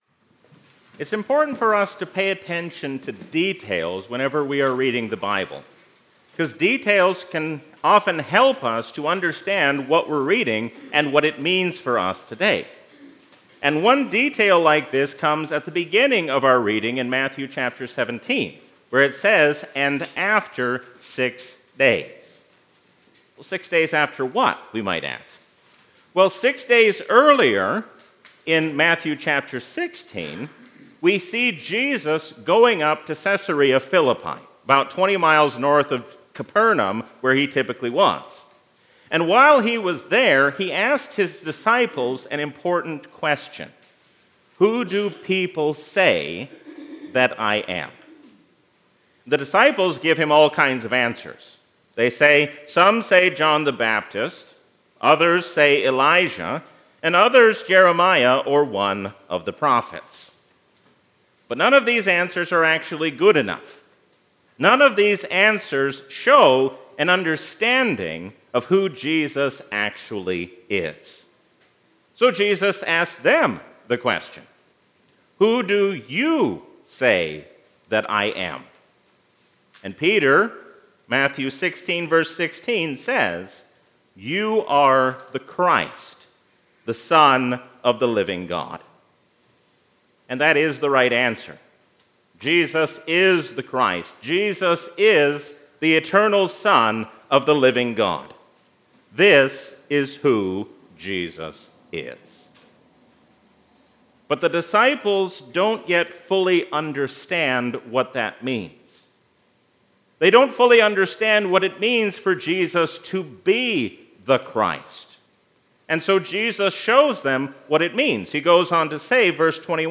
A sermon from the season "Epiphany 2023." Jesus is the beginning of the new creation, so why would we hold on to our old lives any longer?